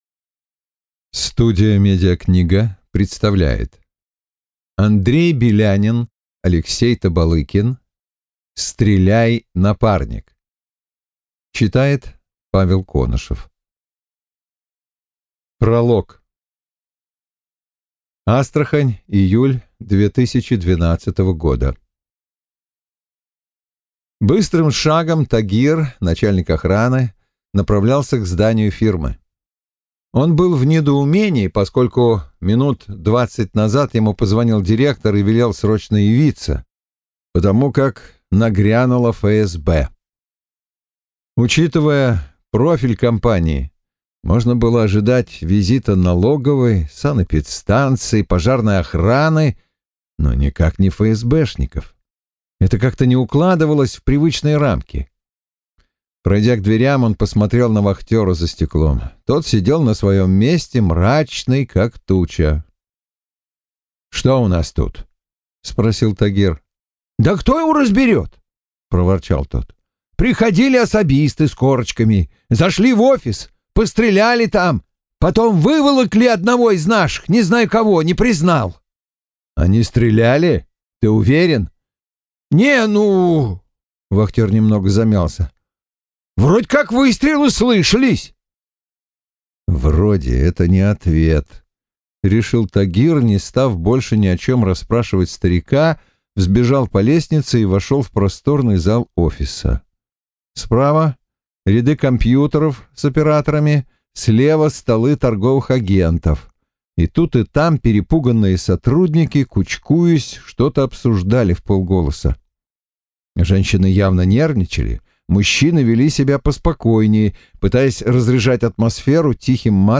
Аудиокнига Стреляй, напарник!